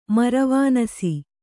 ♪ maravānasi